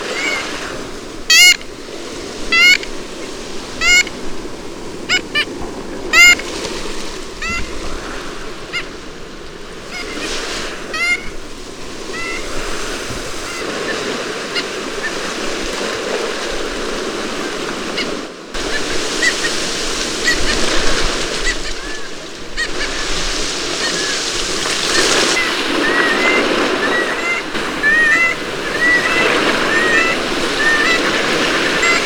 Bridled Tern
Sterna anaethetus
VOZ: El llamado nasal consistente de dos notas y es emitido incesantemente en las colonias de cría.